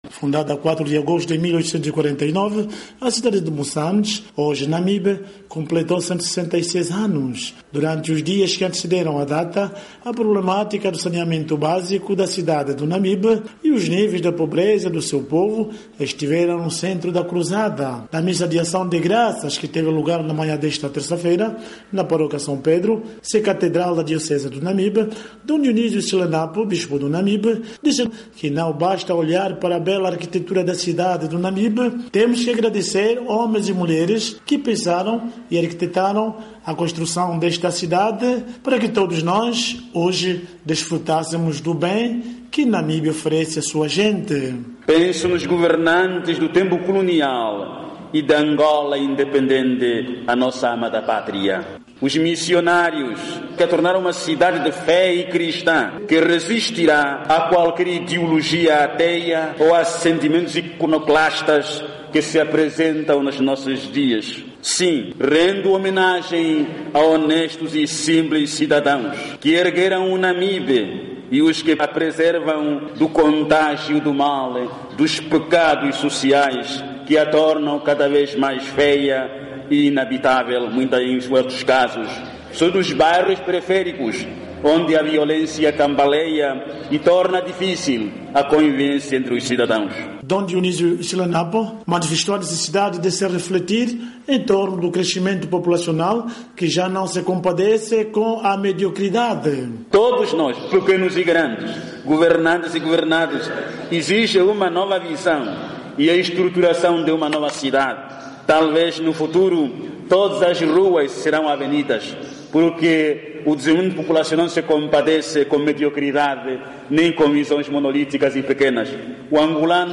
“O crescimento populacional não se compadece com a mediocridade”, alertou Dom Dionísio Hisilenapo, numa missa por ocasião dos 166 anos da fundação da cidade do Namibe, a 4 de Agosto de 1849, na altura com o nome de Moçâmedes.